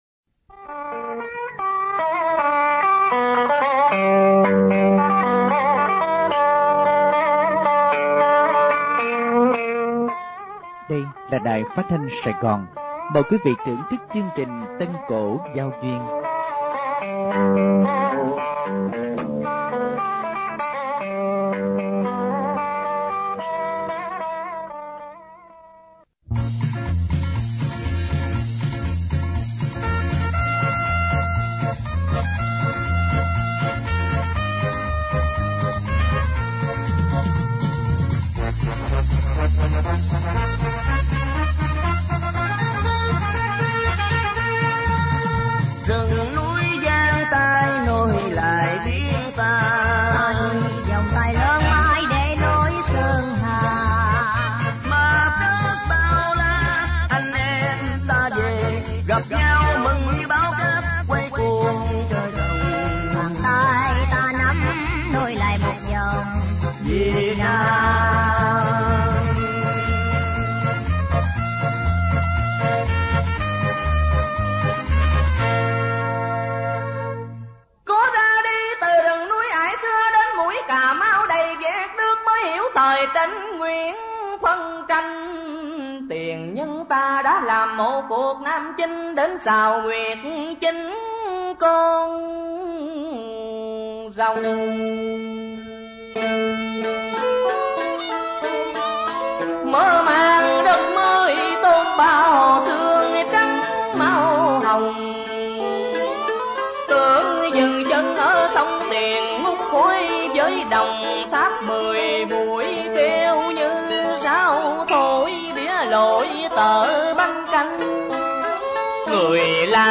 Tân Cổ Giao Duyên 50.